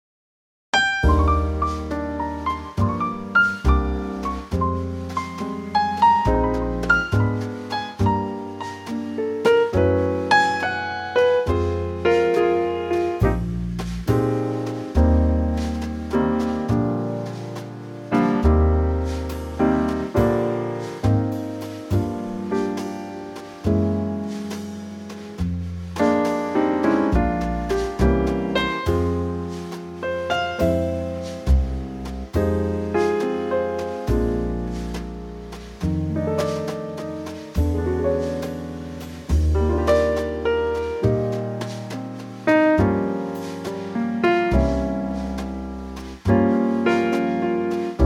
key - Eb - vocal range - Bb to Db
Lovely old 40's standard in a gorgeous Trio arrangement.